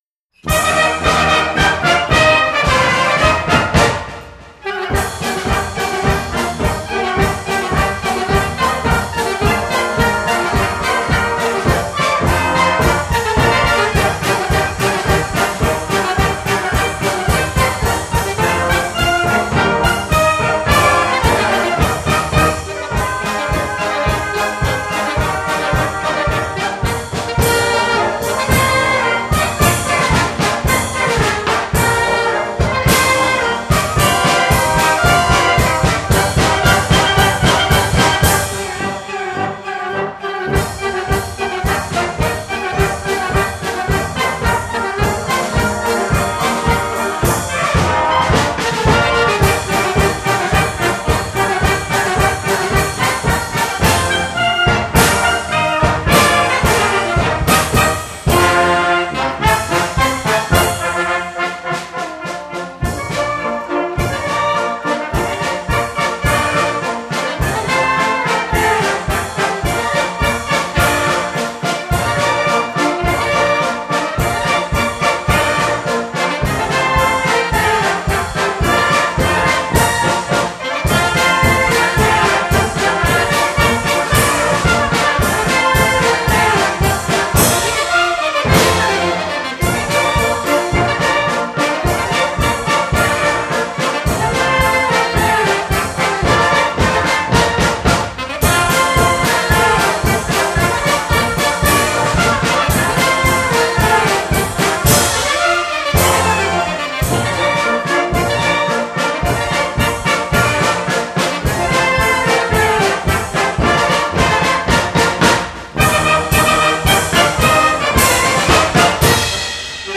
Orkiestra Dęta Okulice